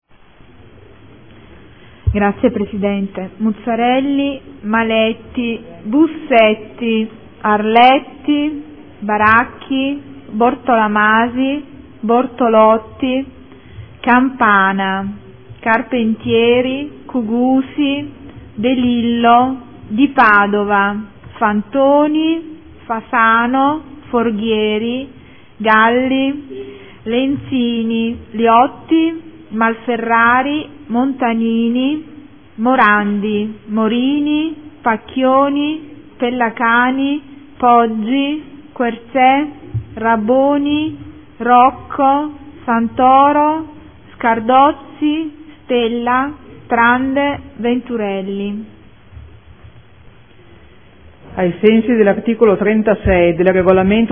Seduta del 27/11/2014 - Appello.
Segretario